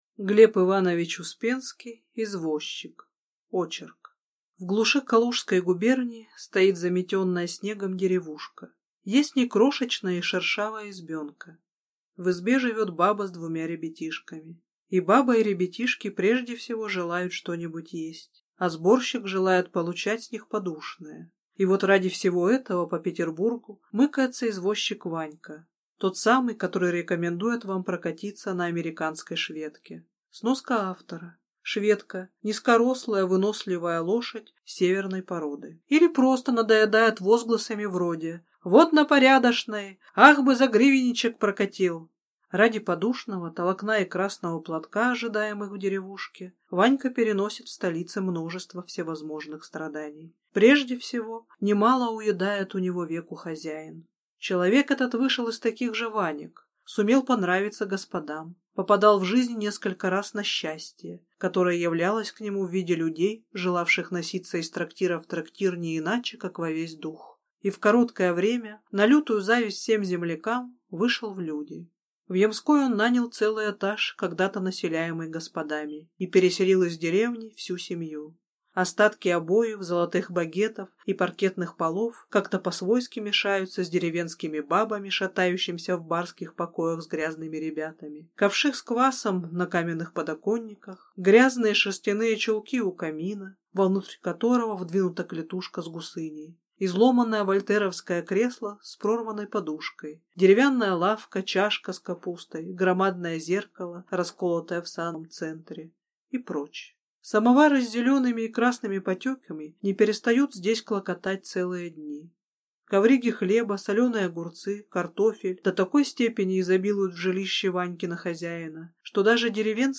Аудиокнига Извозчик | Библиотека аудиокниг